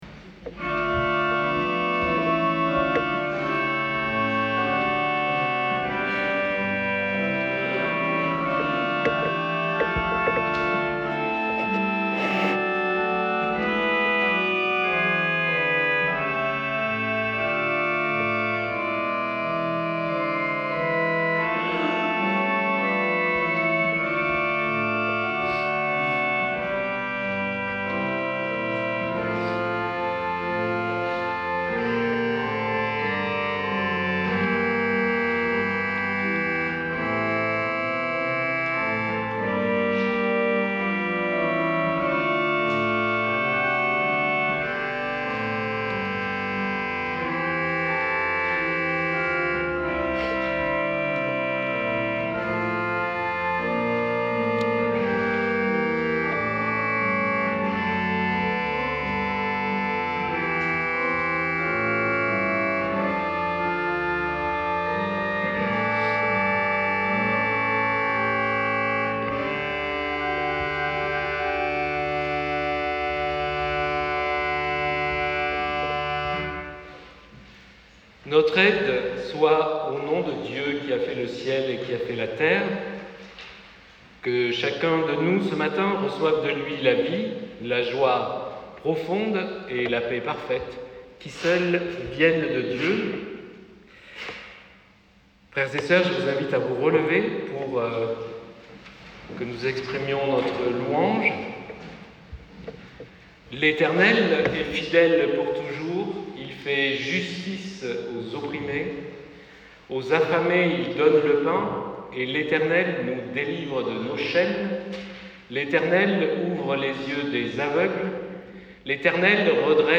Audio culte jusqu'à orgue post prédication inclus.mp3 (52.57 Mo)